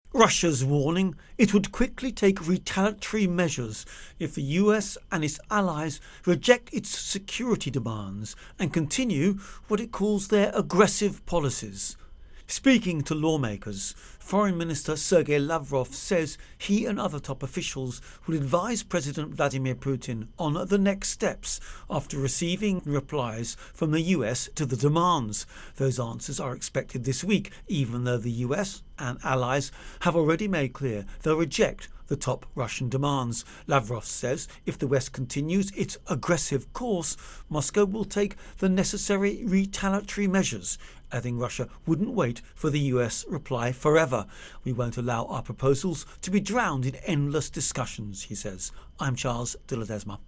Ukraine-Tensions-Russia Intro and Voicer